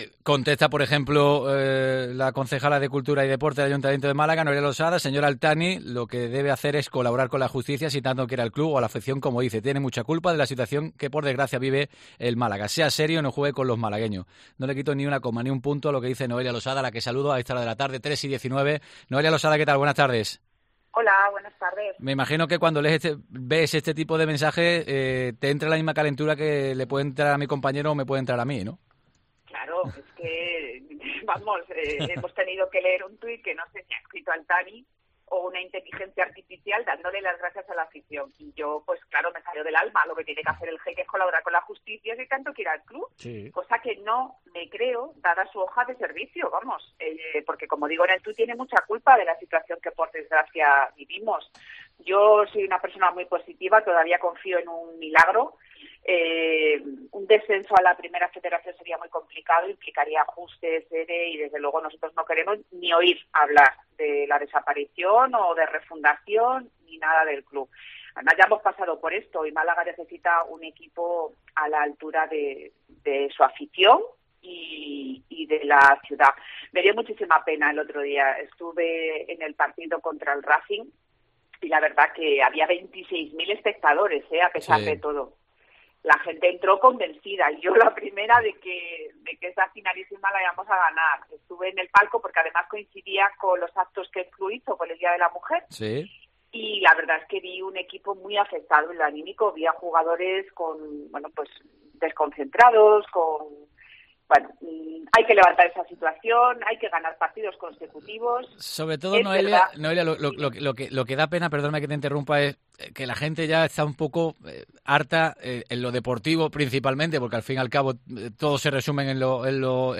La concejala de Cultura y Deporte explica que no estuvo de acuerdo con el escrito que se presentó para modificar la administración judicial en el Málaga C.F.